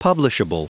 Prononciation du mot publishable en anglais (fichier audio)
Prononciation du mot : publishable